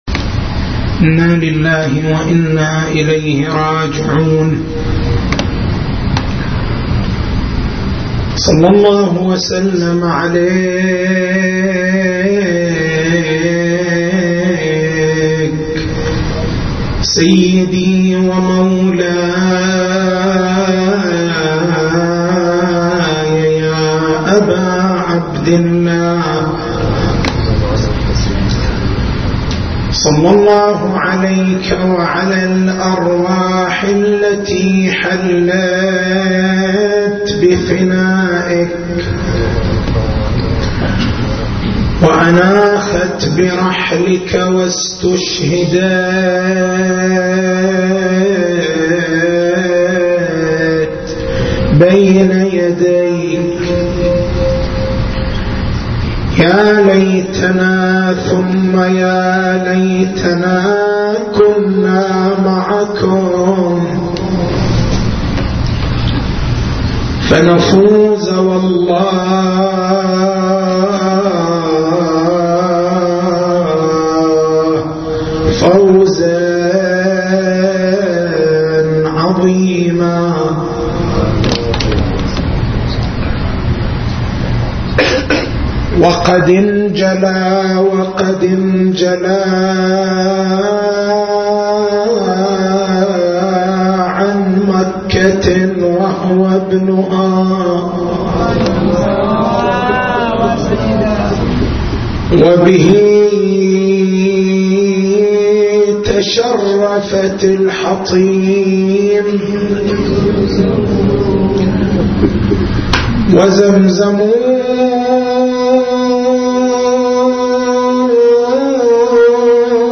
تاريخ المحاضرة: 03/01/1435 نقاط البحث: بيان أن التشيّع إسلام القرآن والسنّة معًا نظرية إسلام القرآن نظرية إسلام الحديث نظرية إسلام القرآن والحديث هل حجّيّة السنّة في طول حجّيّة القرآن أم في عرضها؟